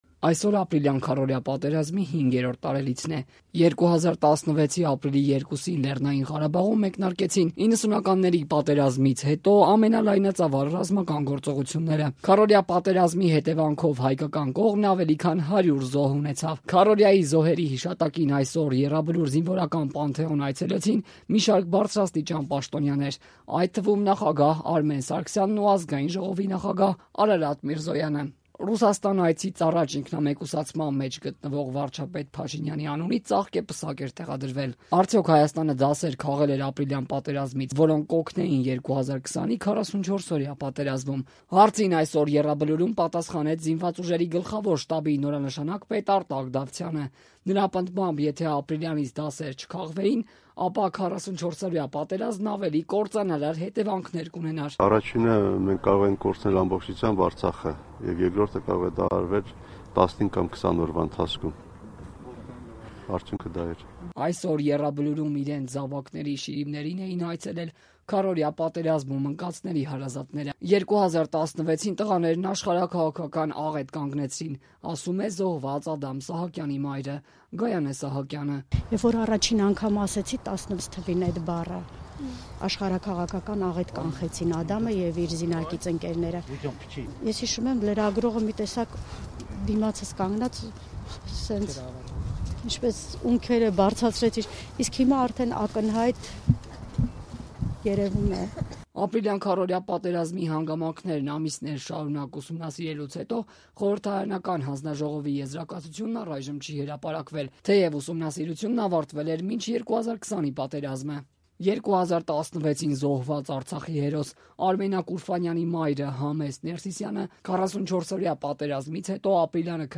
Այսօր ապրիլյան քառօրյա պատերազմի 5-րդ տարելիցն է․ Եռաբլուրը դարձյալ մարդաշատ է
Ռեպորտաժներ